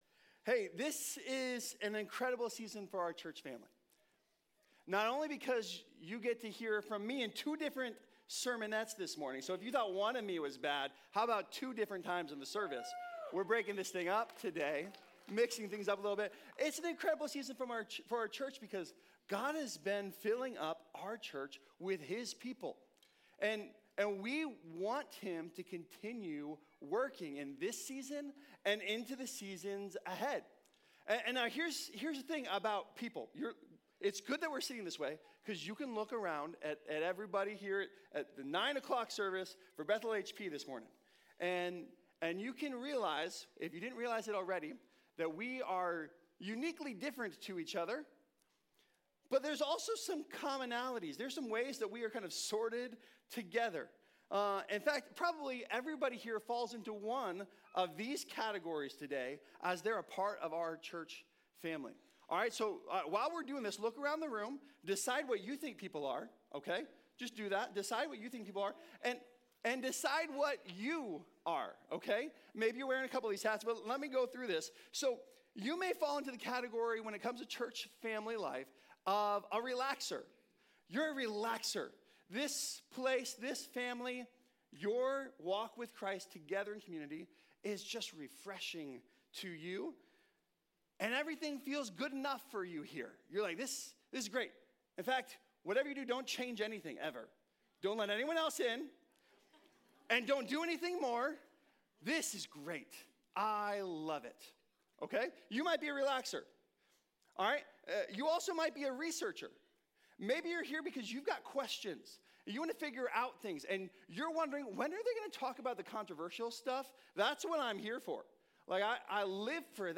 Nehemiah 1 | Reaction, Into Action | Nehemiah - HP Campus Sermons